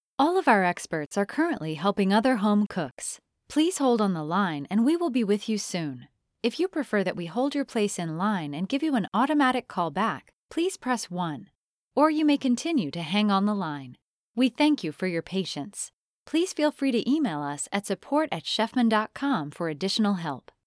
standard us
phone message
2_COH-1_Chefman_on_hold.mp3